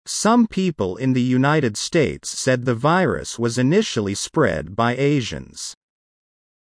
このままの速度でお聞きください。
ディクテーション第2問
【ノーマル・スピード】